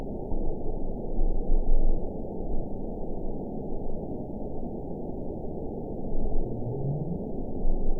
event 912819 date 04/03/22 time 08:16:40 GMT (3 years, 1 month ago) score 9.14 location TSS-AB03 detected by nrw target species NRW annotations +NRW Spectrogram: Frequency (kHz) vs. Time (s) audio not available .wav